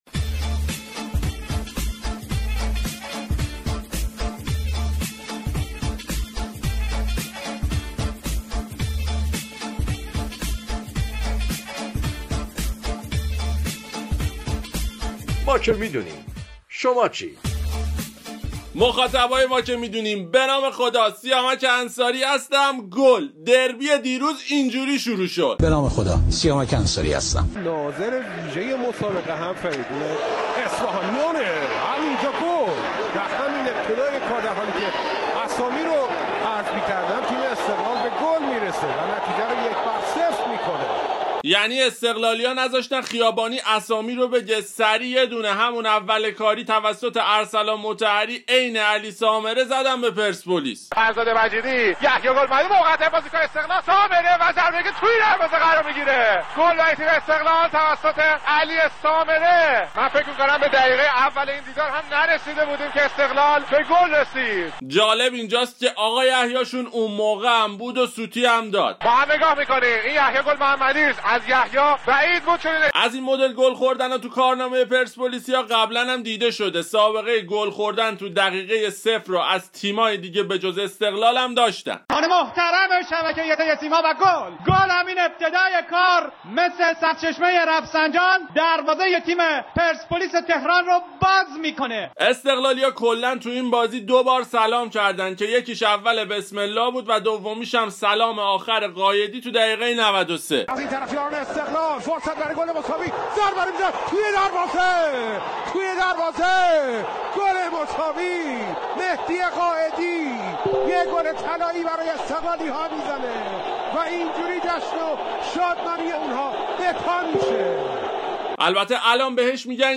آیتم طنز